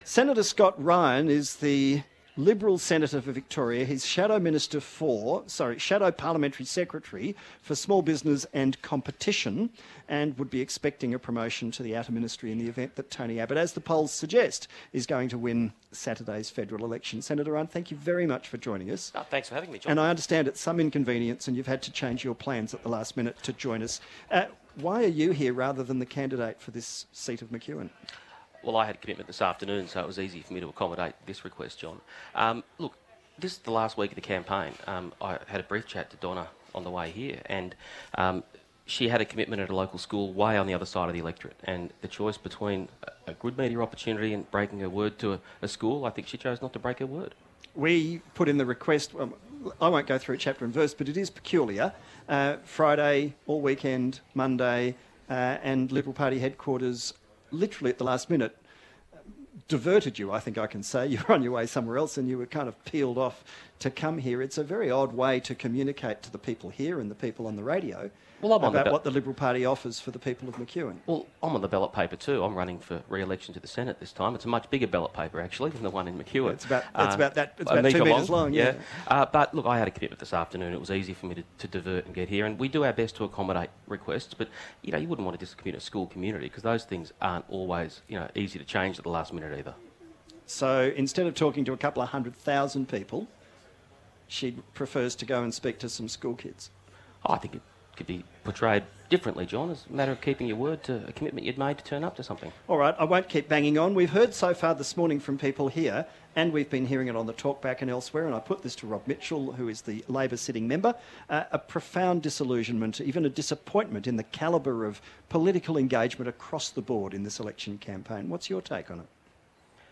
Senator Ryan joined the Jon Faine Morning Program on 774 ABC radio for an outside broadcast in the electorate of McEwen ahead of Election Day. Senator Ryan talked about the electorate, candidates and the approaching Election.
Senator Ryan joined Jon Faine for an outside broadcast in the electorate of McEwen ahead of Election Day